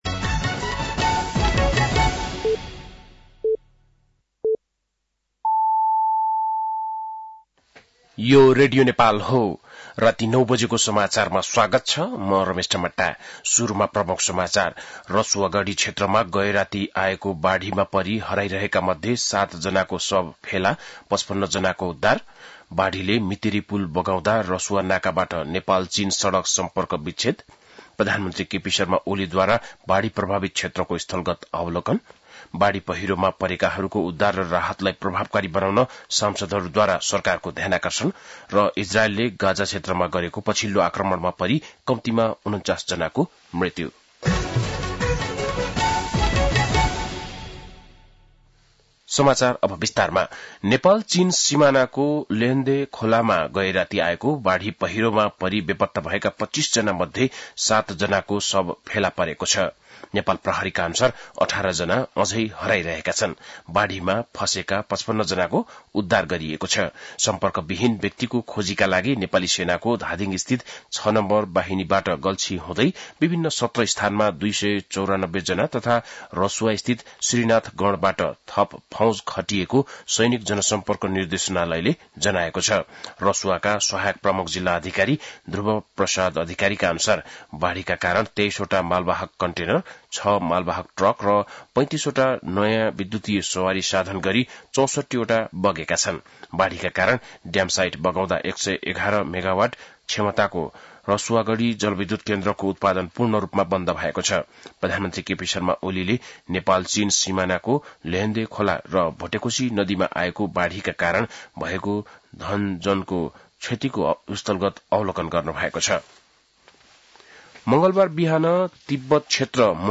बेलुकी ९ बजेको नेपाली समाचार : २४ असार , २०८२
9-pm-nepali-news-3-24.mp3